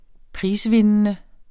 Udtale [ -ˌvenənə ] eller [ -ˌvenˀənə ]